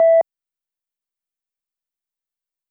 play_beep.wav